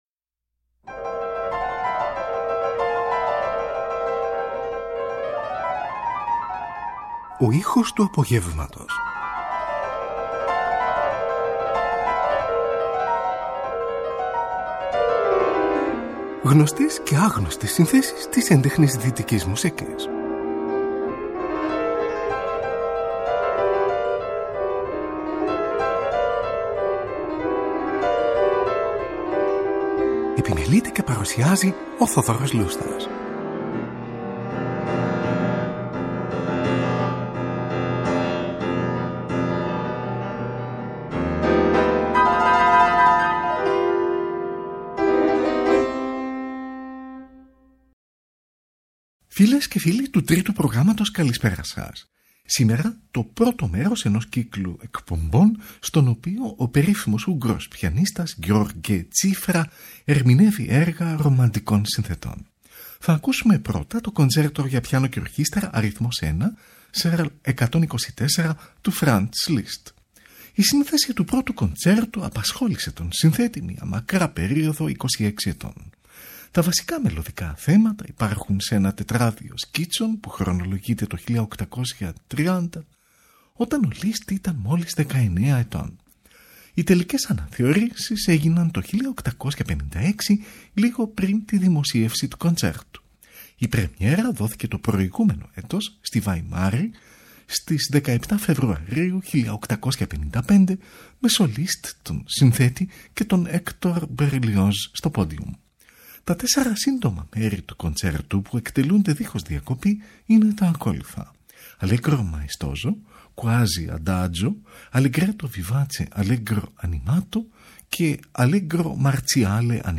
O Ούγγρος Πιανίστας György Cziffra Ερμηνεύει Έργα Ρομαντικών Συνθετών – 1o Μέρος | Δευτέρα 16 Δεκεμβρίου 2024
Franz Liszt: Φαντασία και Φούγκα Πάνω στο θέμα B-A-C-H, στην εκδοχή για πιάνο , S. 529.